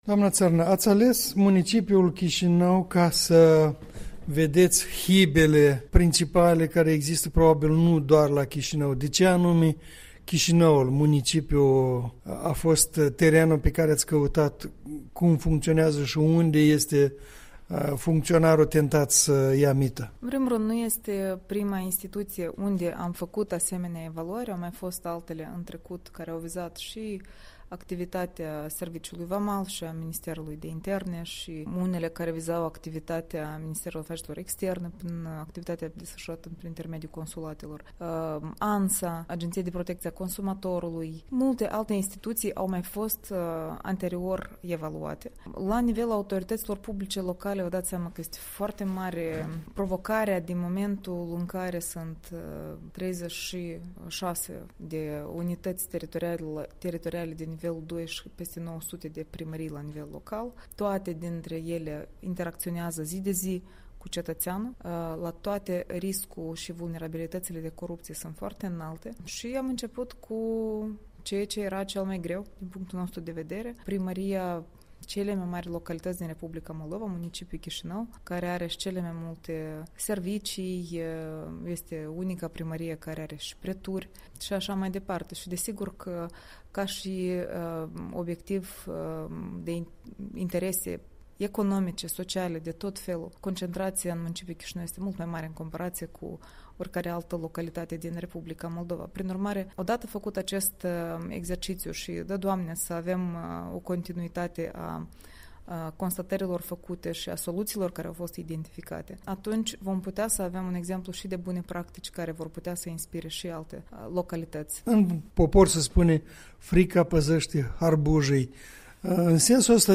Interviul matinal cu directoarea adjunctă a Centrului Naţional Anticorupţie de la Chișinău.